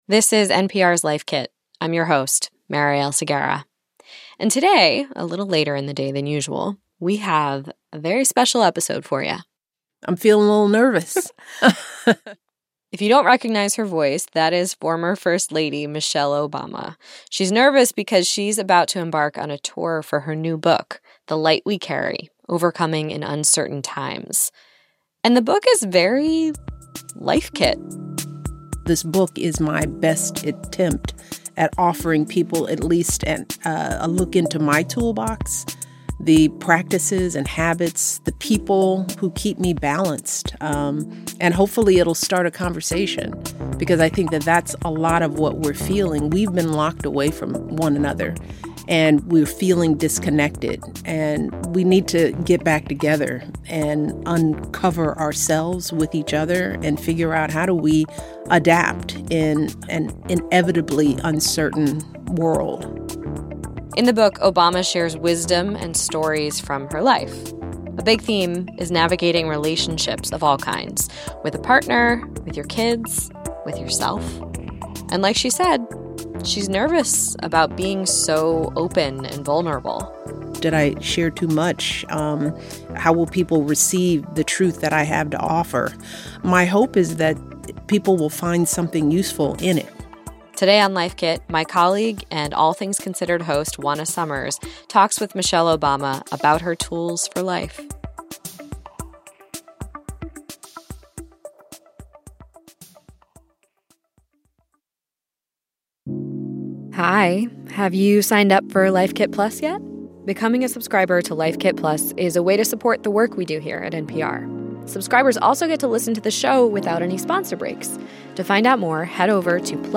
Former first lady Michelle Obama talks to Life Kit about her new book The Light We Carry: Overcoming in Uncertain Times